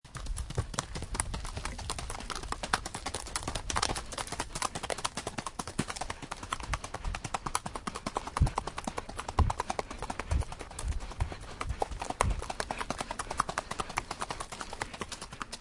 Paso Fino Horse Téléchargement d'Effet Sonore